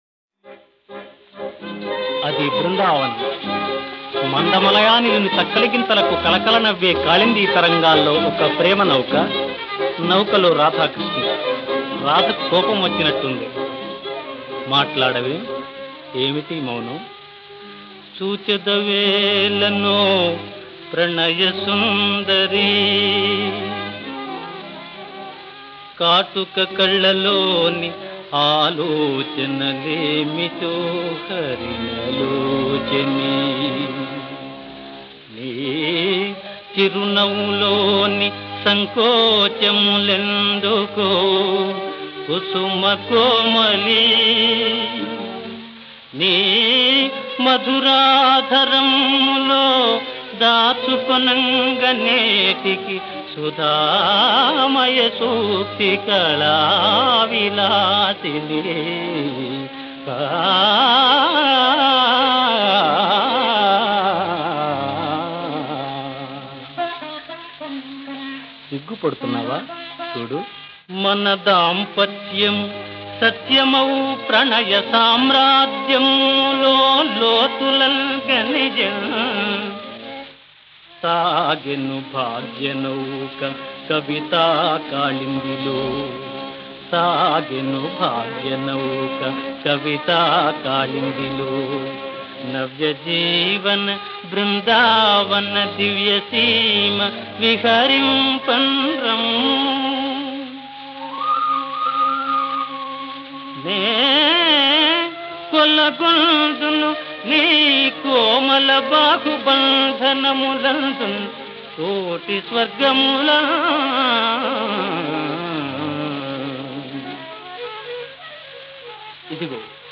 మోహన రాగంలో
హిందోళం రాగంలో బాణీ కాబడ్డ ఈ క్రింది పద్యం